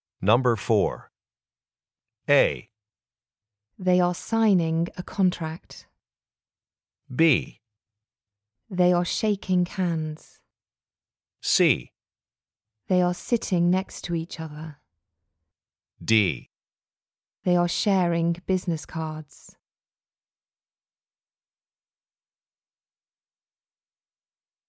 For each question in this part, you will hear four statements about a picture in your test book.
The statements will not be printed in your test book and will be spoken only one time.